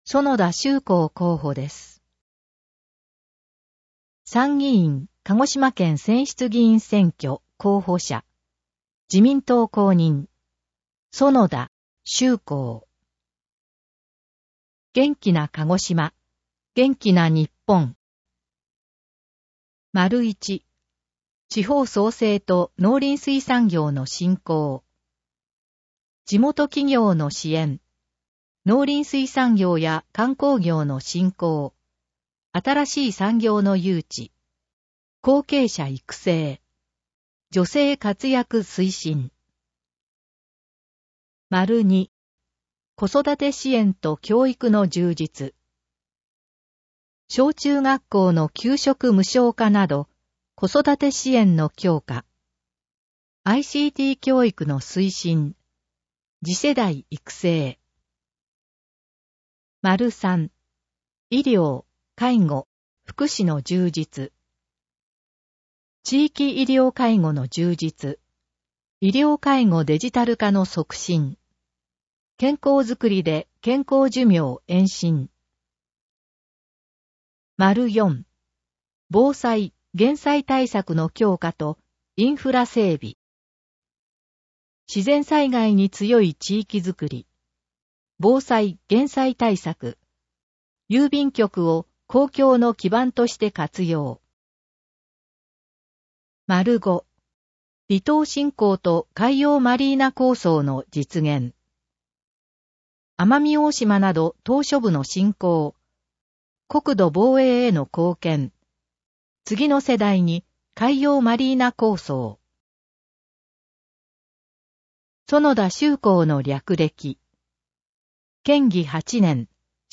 音声読み上げ対応データ（MP3：376KB）